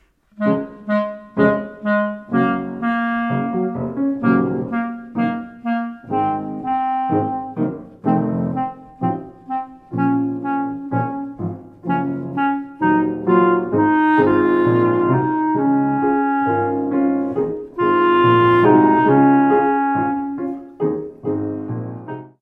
clarinet
harp
piano.